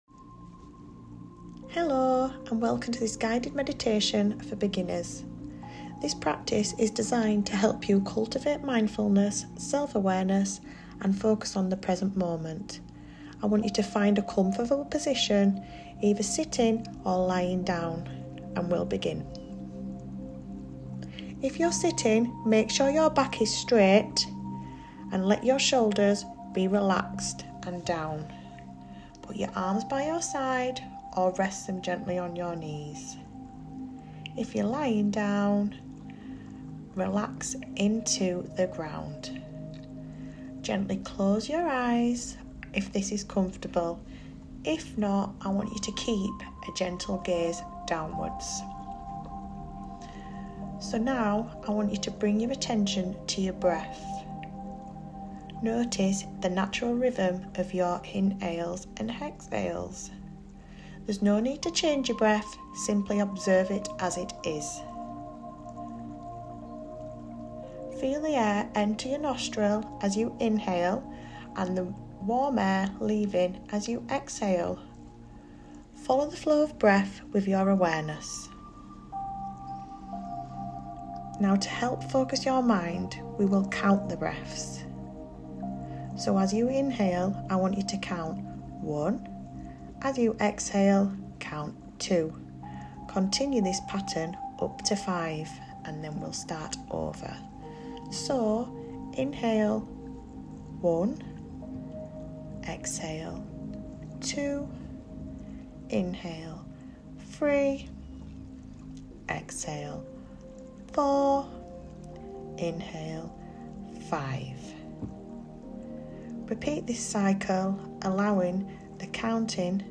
Guided Meditation.mp3